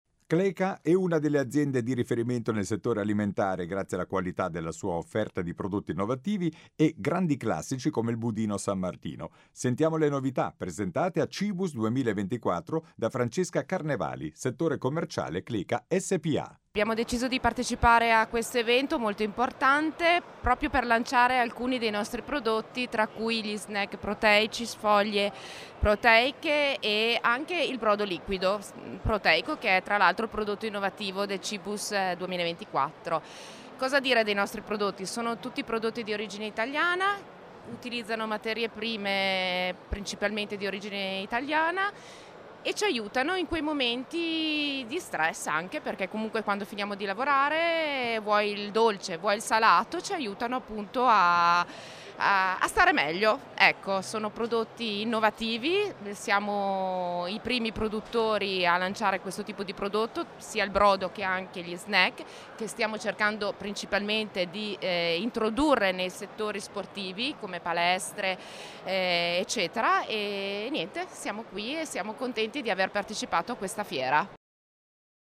Qui di seguito le dichiarazioni raccolte dal nostro inviato sul posto